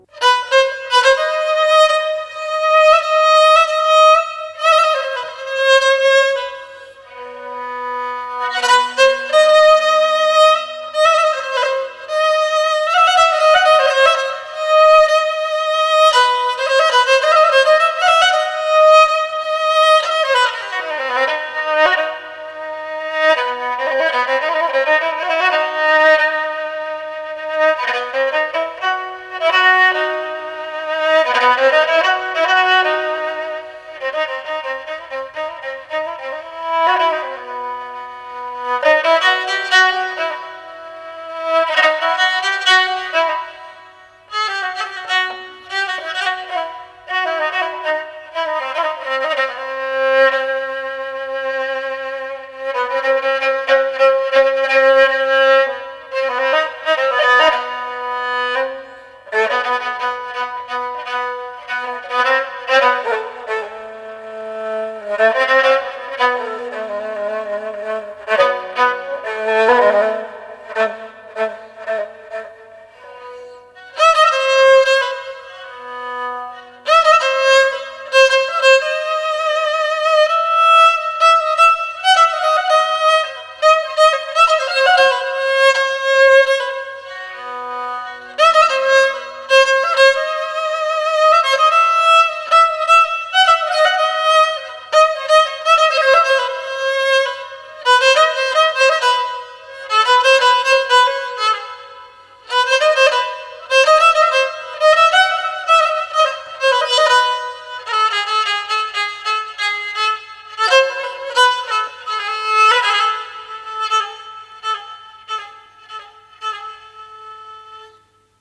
Bedahe Navazie Kamancheh